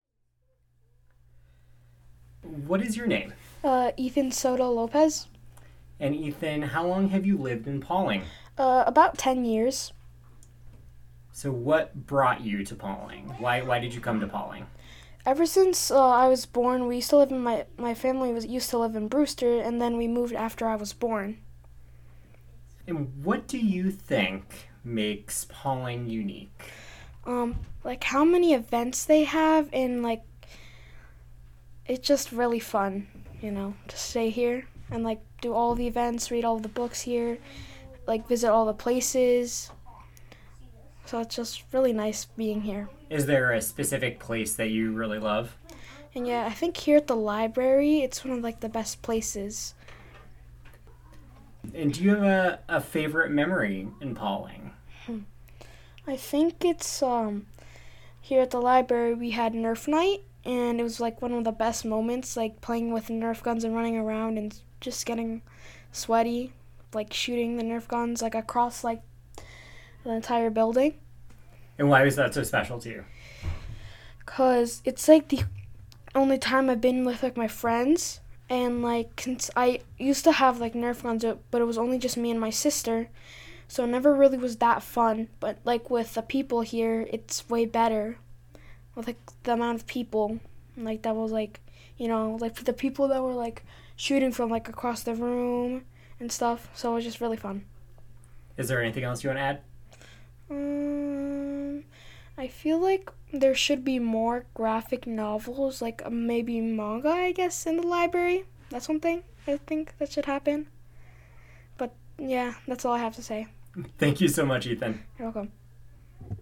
The conversation was recorded as part of the People of Pawling Project. The project saught to create brief snapshots of Pawling that could be easily consumed and show the richness of the community during the time that the recordings were done.